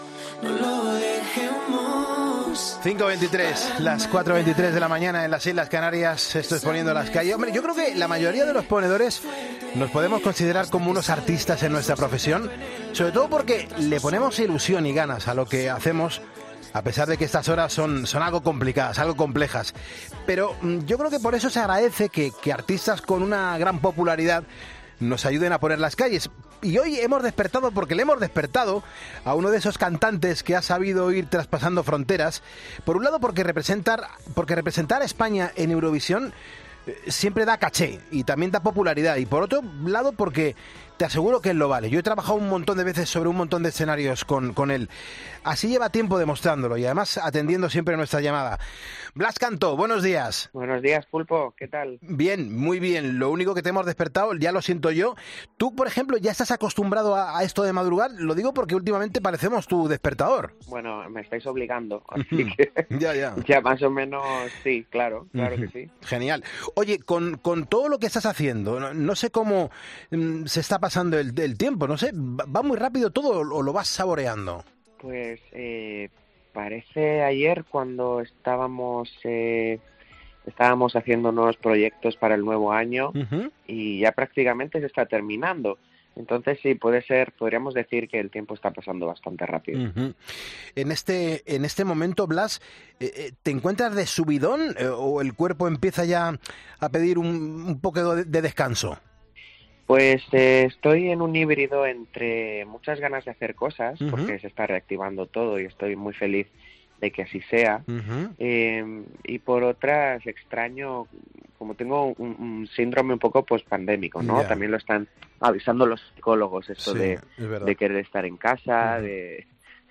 El cantautor español se ha pasado por 'Poniendo las Calles' para hablarnos de su último tema, de sus proyectos de futuro y de cómo ha vivido la...